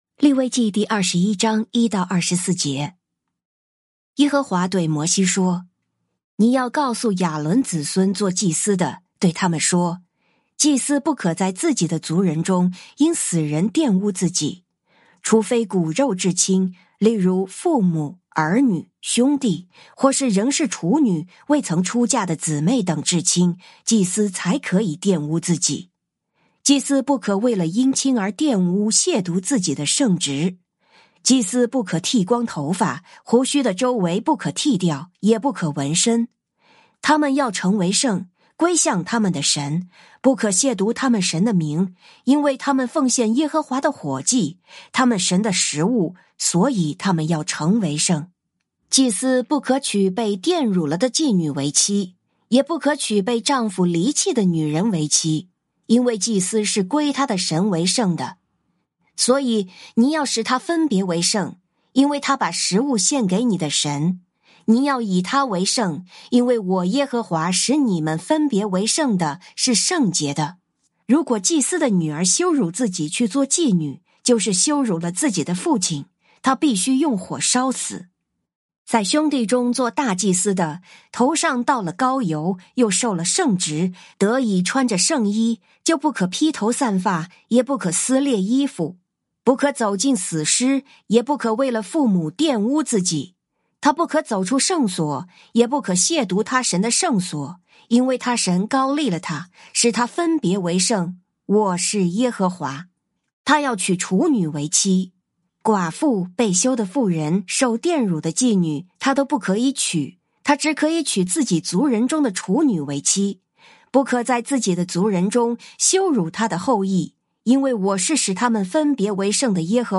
靈修分享：利21章1-24節「放下自己的權利」
「天父爸爸說話網」是由北美前進教會Forward Church 所製作的多單元基督教靈修音頻節目。